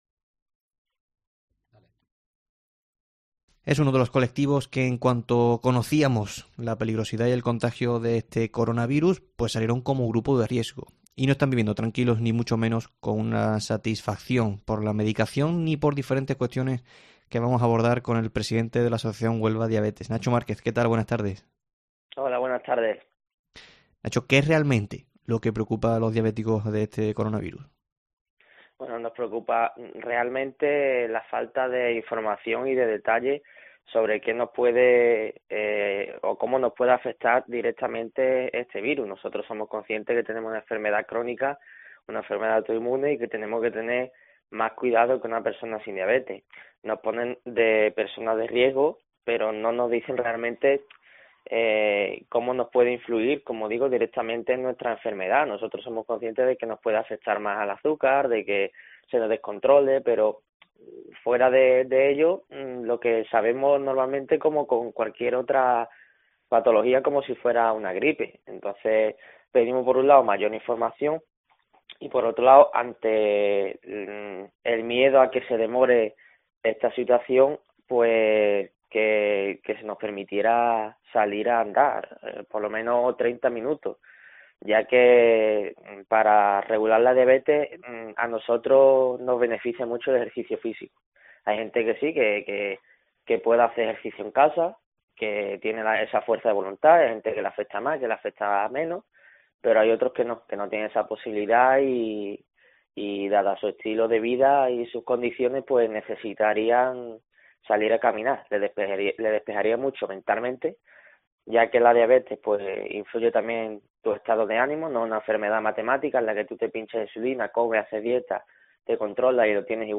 En el tiempo local de Mediodia COPE hablamos con